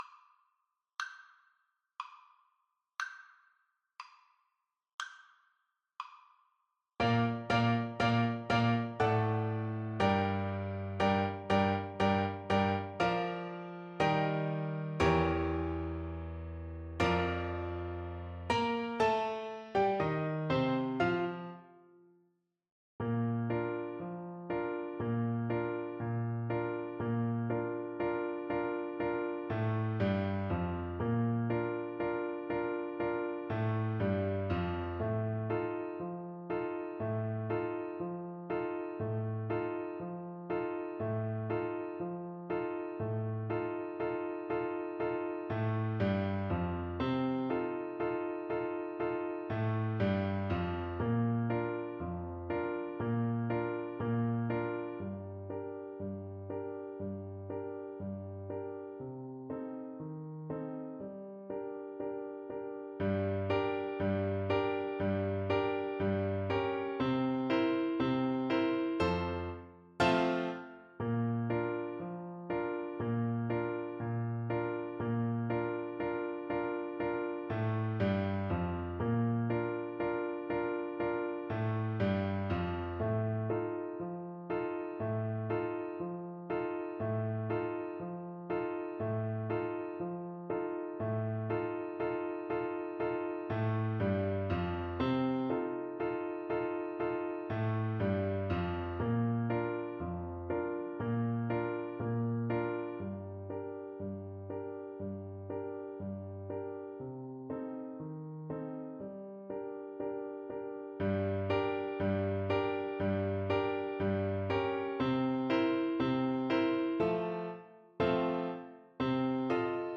Play (or use space bar on your keyboard) Pause Music Playalong - Piano Accompaniment Playalong Band Accompaniment not yet available transpose reset tempo print settings full screen
Flute
Bb major (Sounding Pitch) (View more Bb major Music for Flute )
2/2 (View more 2/2 Music)
Marziale = c. 100
Classical (View more Classical Flute Music)